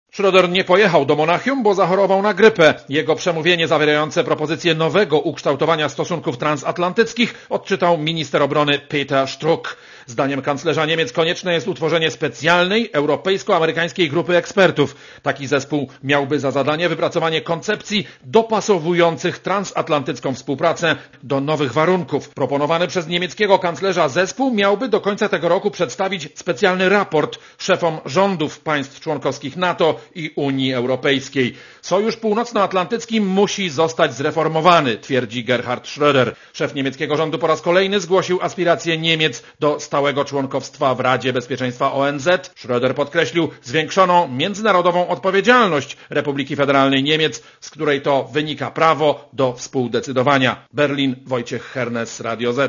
Korespondencja z Niemiec